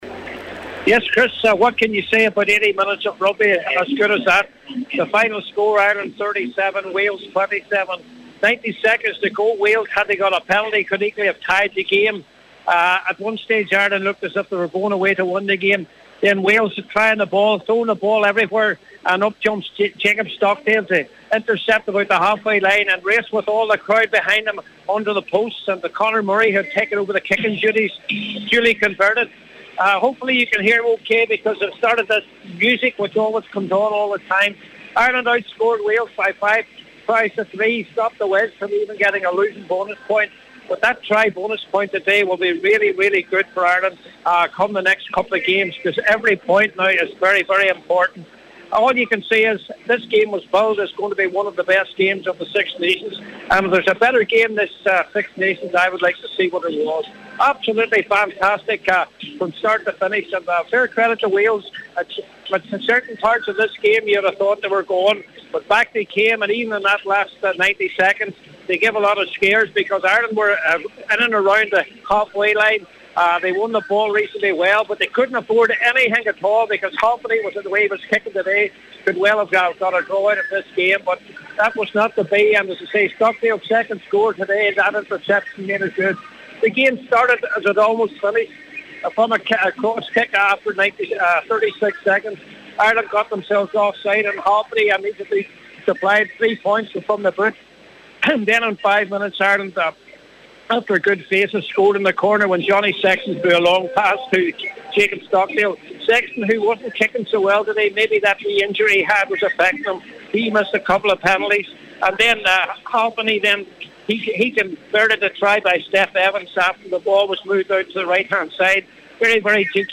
full-time report